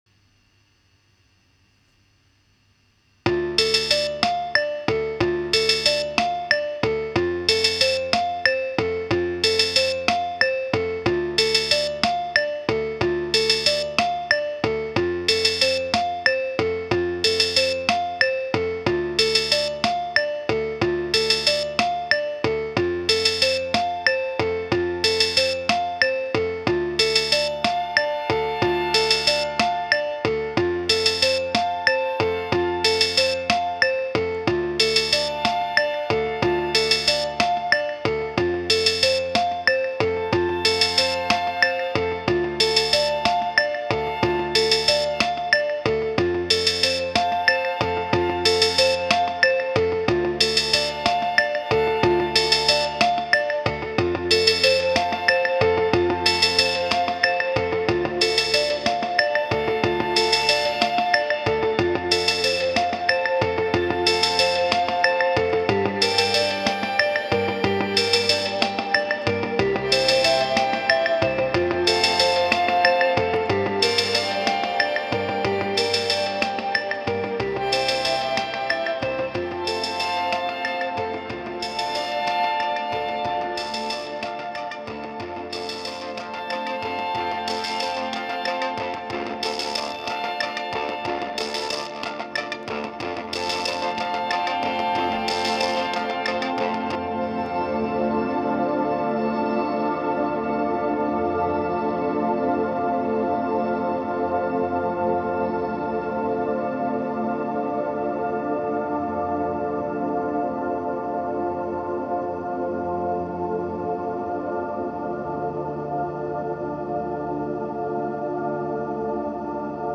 C’est ici que j’explore une idée alternative avec d’autres sonorités: en particulier via le clavier sampler Casio SK-1 qui a des sons bien charactéristiques. Il y a aussi une sorte de jouet (Mini Sax) qui sonne un peu comme un harmonica